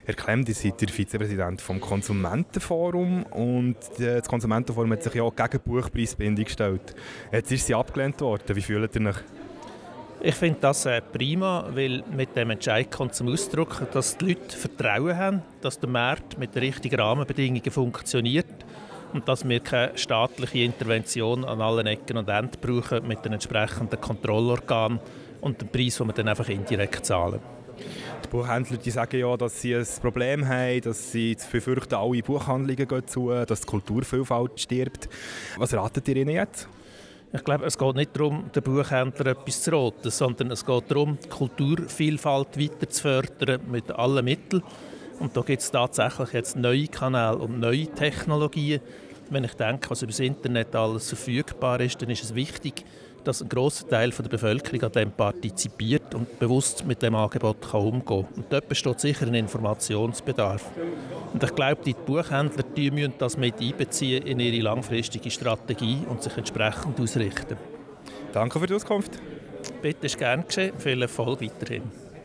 Abstimmungsparty